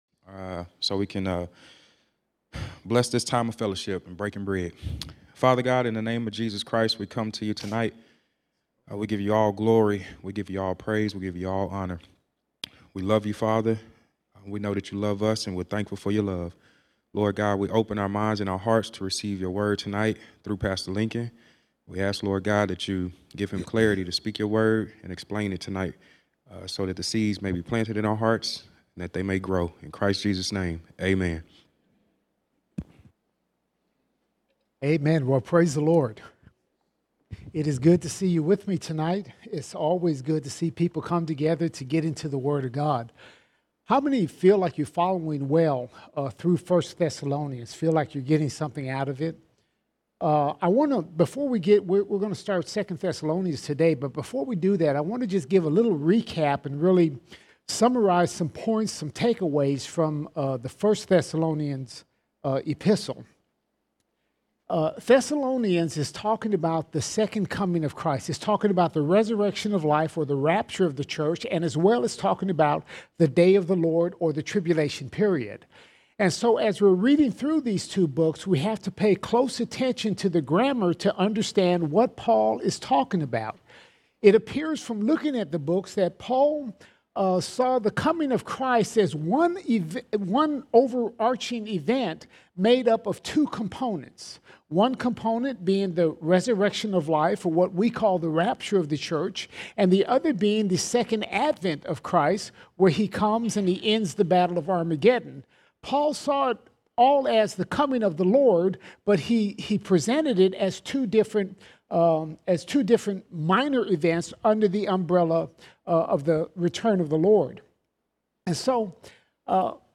5 August 2021 Series: 1 & 2 Thessalonians All Sermons 2 Thessalonians 1:1 to 2:4 2 Thessalonians 1:1 to 2:4 We continue to cover the Day of the Lord and the Rapture of the Church.